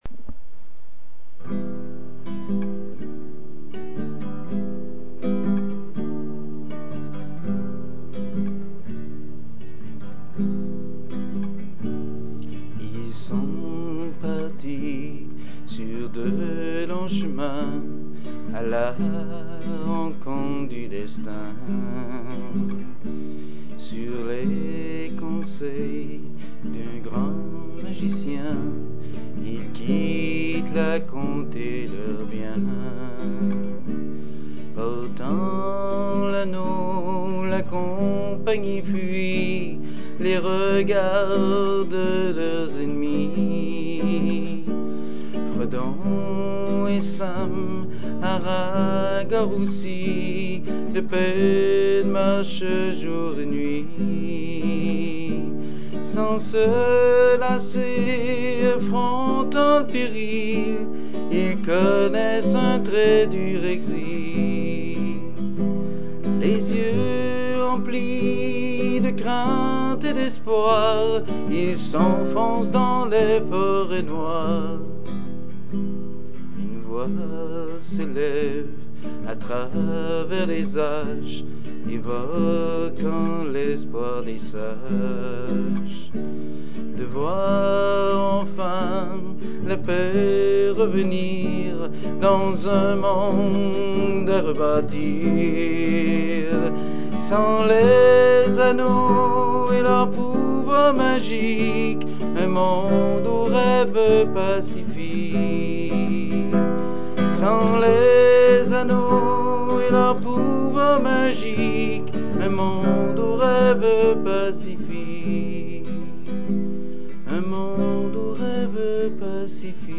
La chanson a été construite sous forme de canon, afin de pouvoir l’a chanter en groupe et créer un effet majestueux…